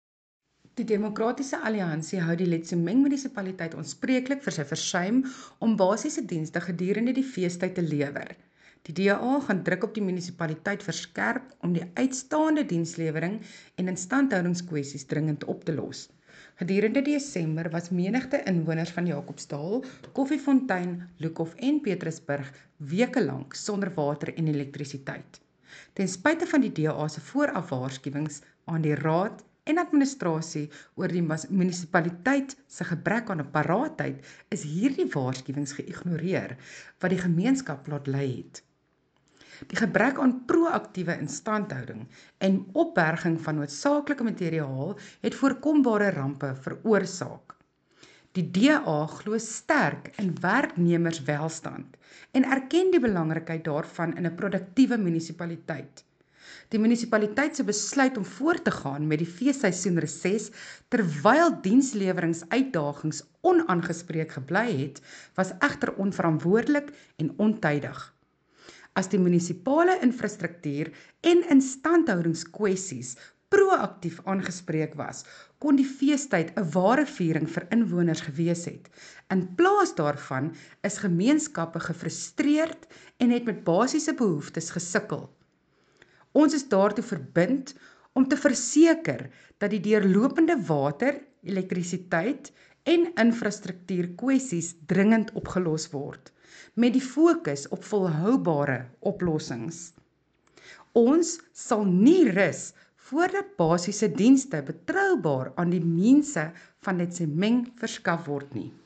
Afrikaans soundbites by Cllr Mariska Potgieter and